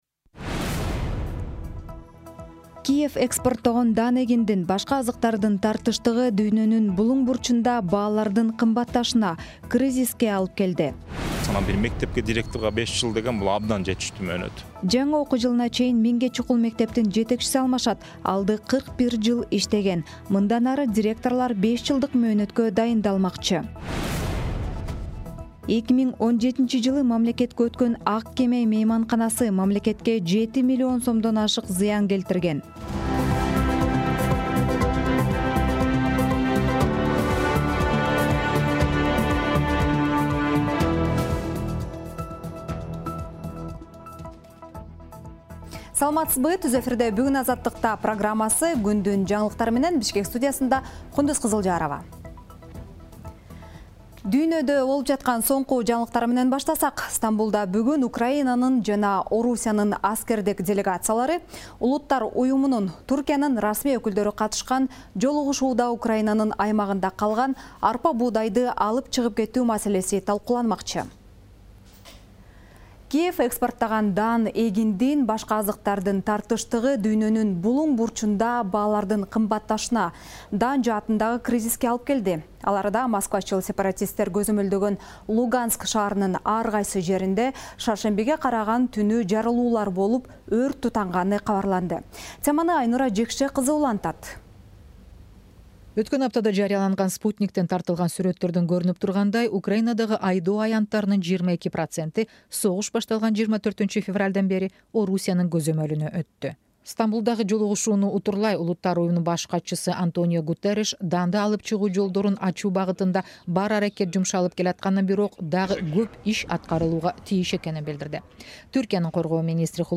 Жаңылыктар | 13.07.2022 | Украинадагы согуш азык-түлүк кризисине алып келди